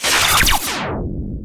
laserout.wav